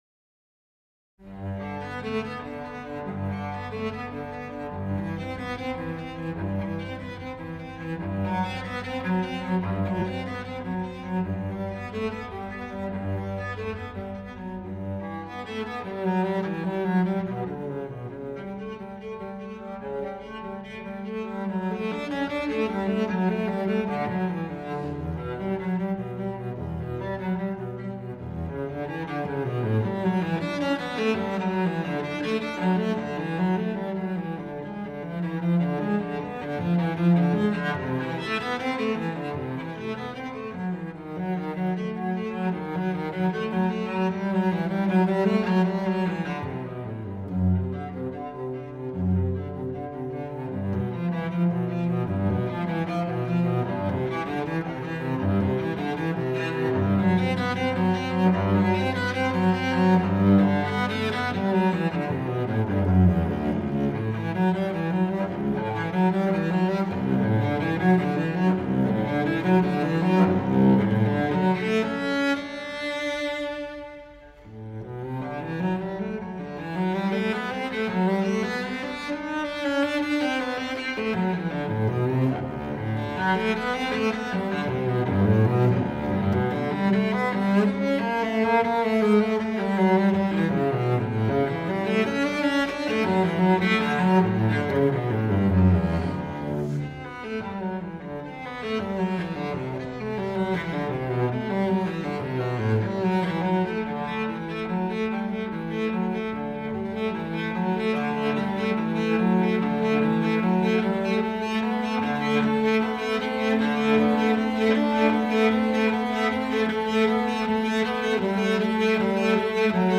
Unaccompanied-Cello-Suite-No.-1-in-G-Major-BWV-1007-I.-Prélude.mp3